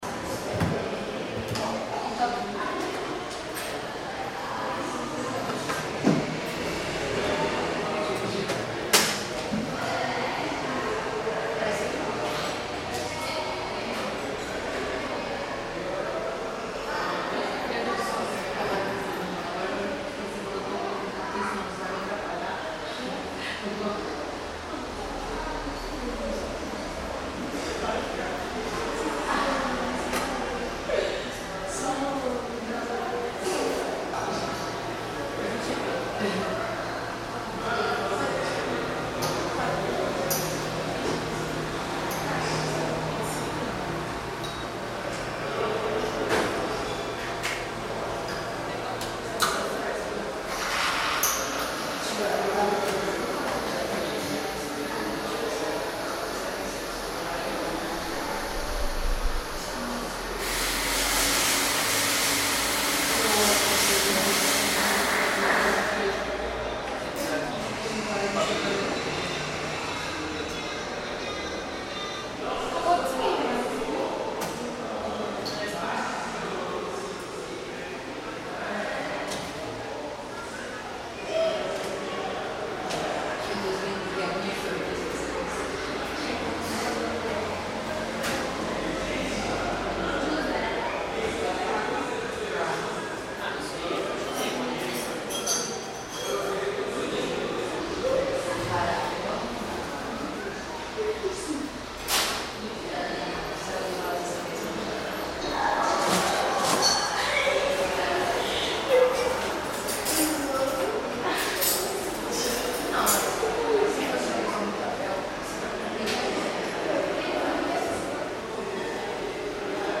A cosy coffee shop in Sao Paulo
A quiet coffee shop where you can hear customers and staff in the background, as well as some espressos coming out, milk steaming and coffee beans grinding. It's the kind of white noise that can help you focus.
Recorded in Sao Paulo, Brazil